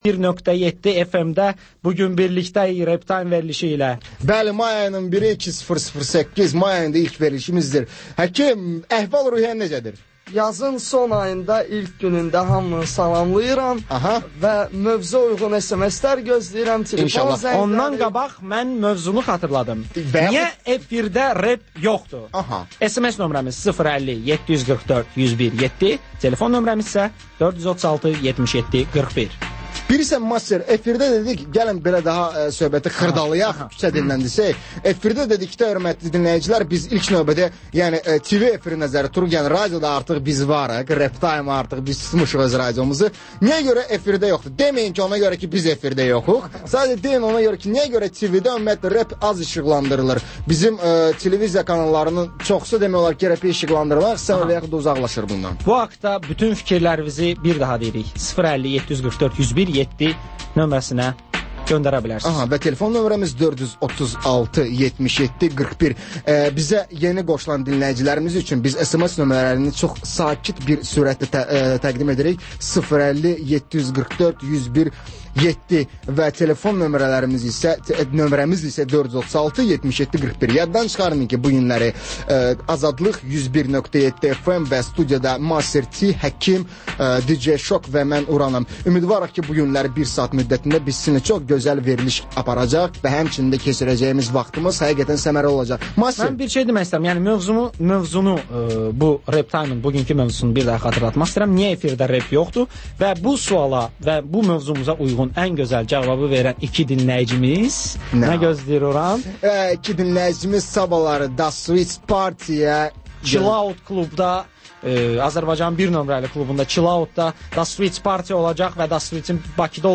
Gənclərin musiqi verilişi